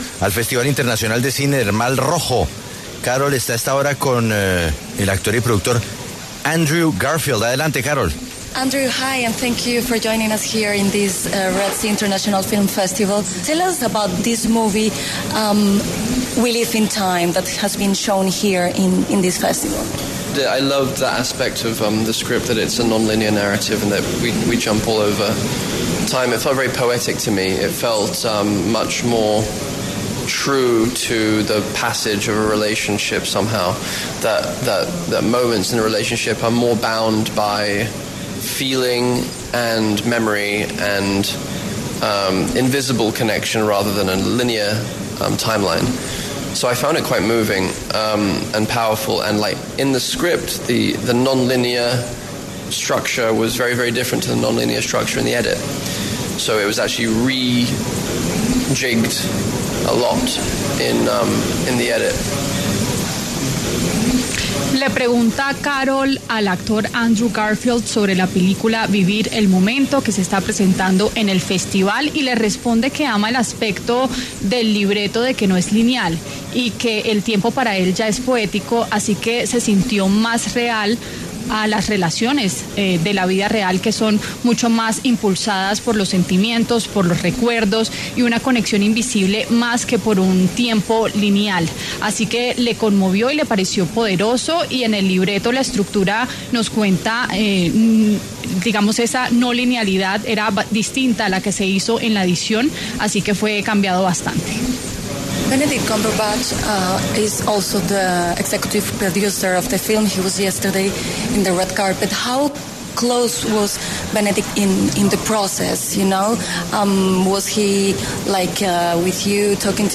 El actor y productor británico Andrew Garfield habló en La W sobre la película ‘We Live In Time’.